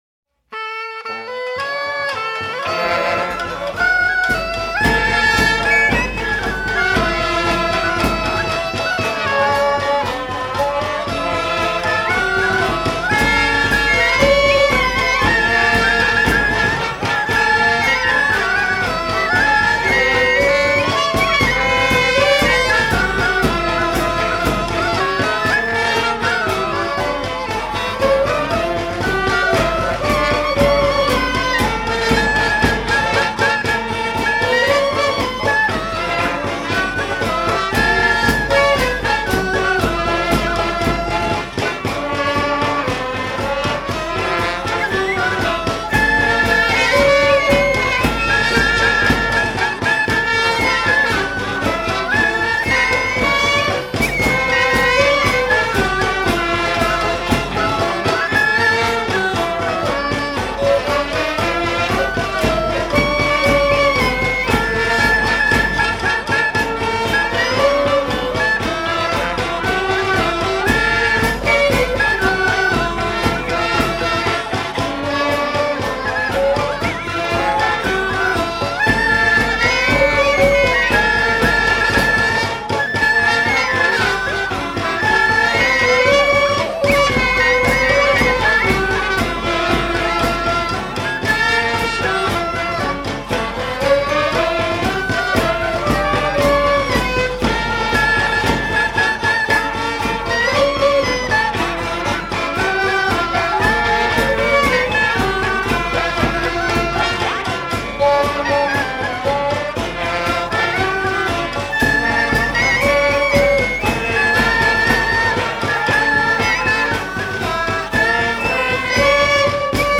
生活の強度が自由な音として吹き荒れる！